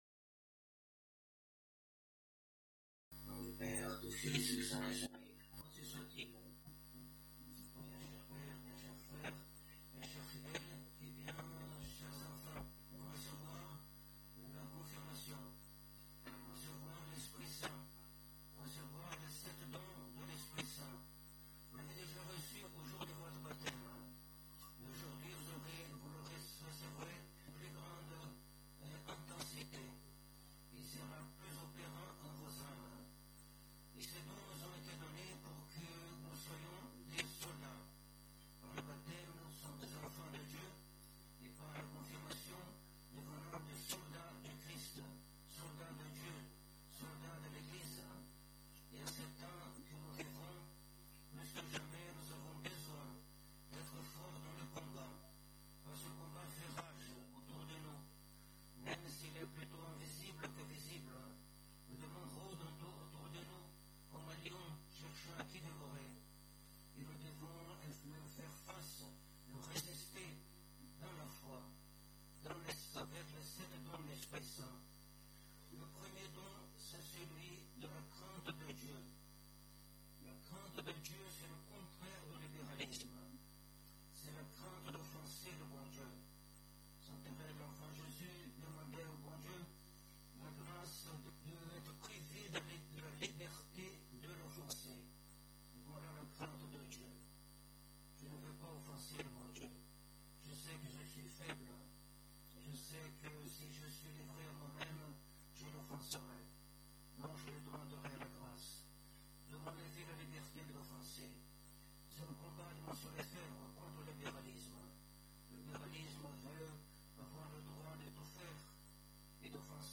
Occasion: Cérémonie : Confirmation
Type: Sermons